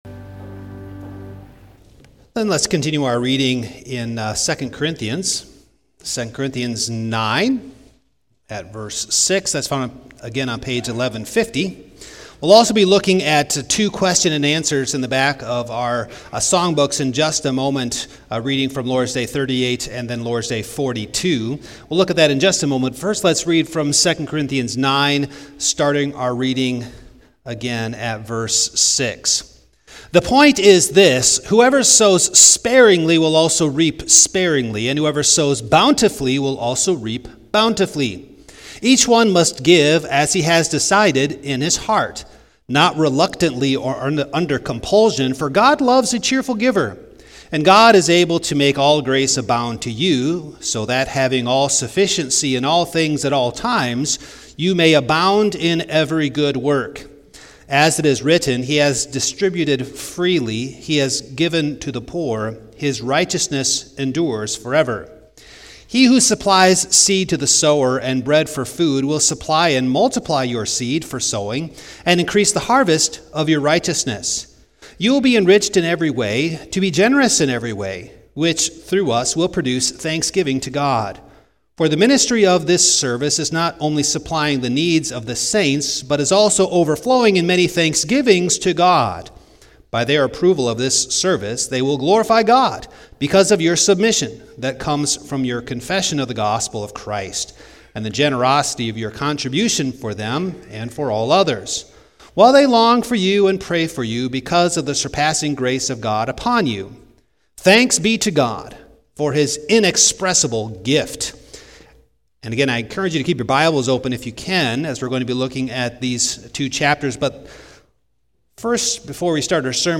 Passage: II Cor. 9:6-15 Service Type: Evening